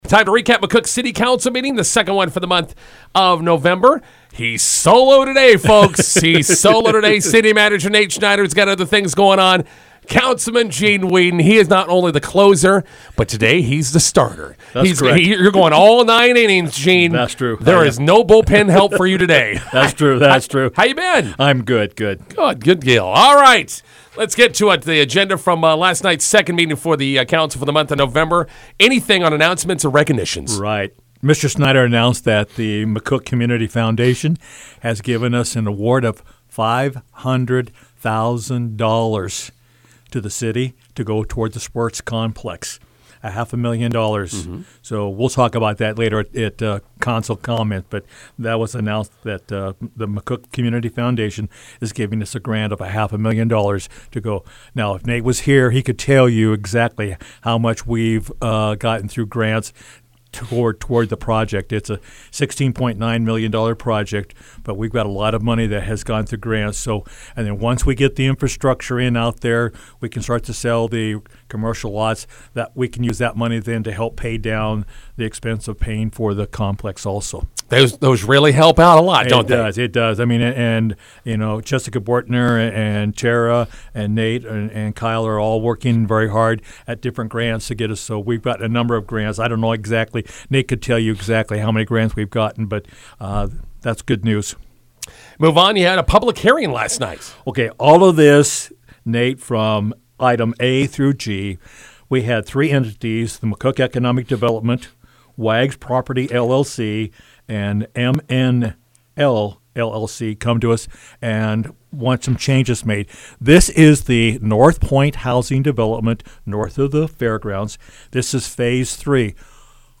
INTERVIEW: McCook City Council meeting recap with Councilman Gene Weedin.